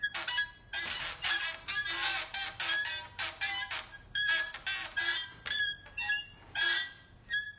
pulley.ogg